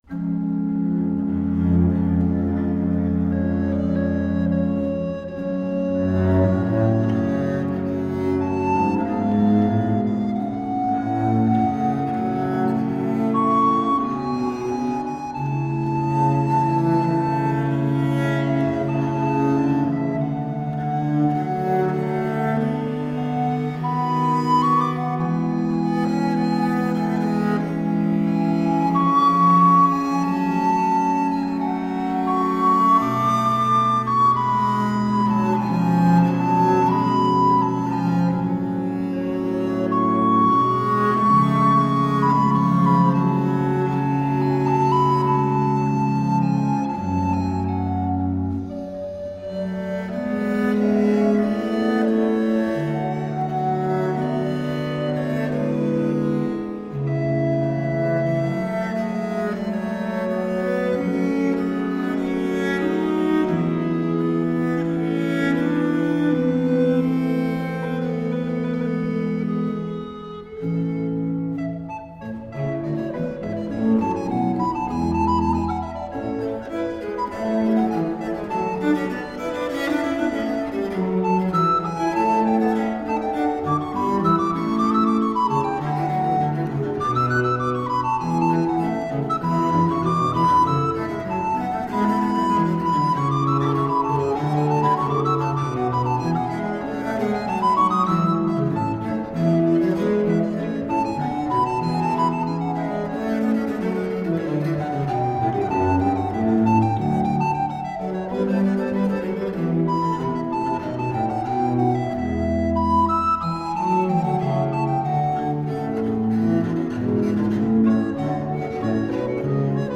Richly textured 17th and 18th century cello and recorder.
It's a richly textured work, but always light on its feet.
Classical, Baroque, Instrumental, Cello
Harpsichord
Organ
Viola da Gamba